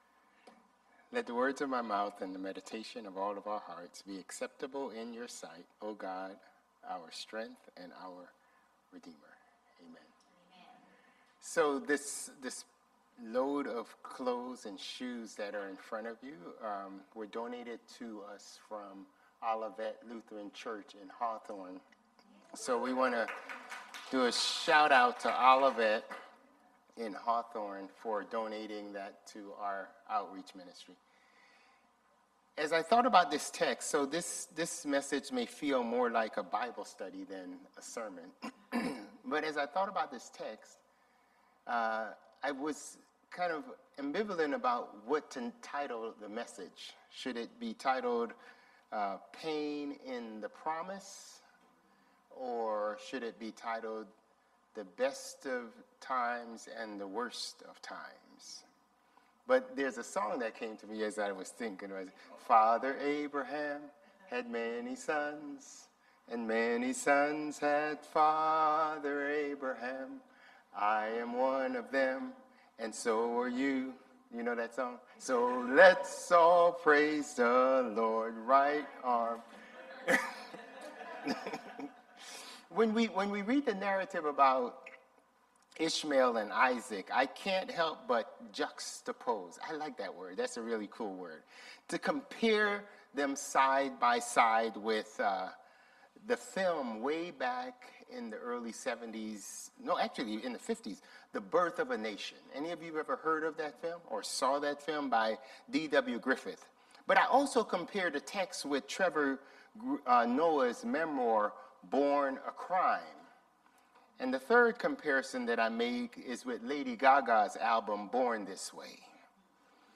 Sermons | Bethel Lutheran Church
October 12 Worship